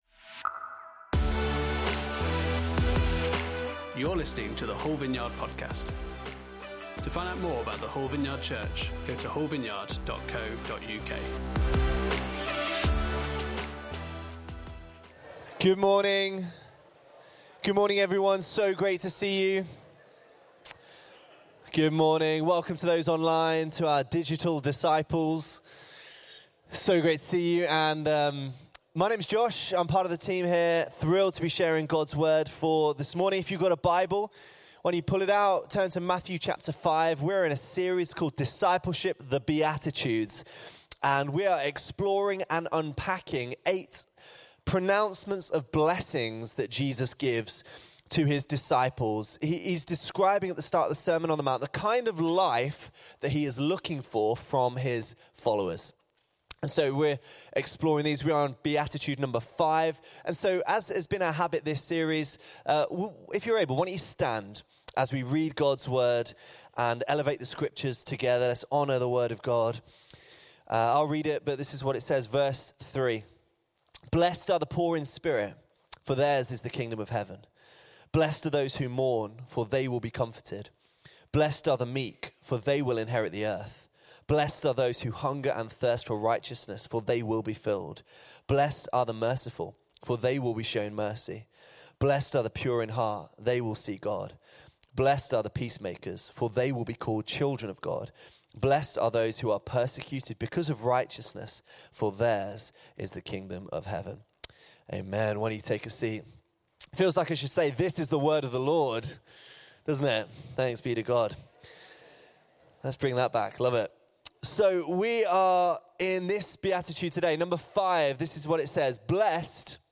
Series: Discipleship: The Beatitudes Service Type: Sunday Service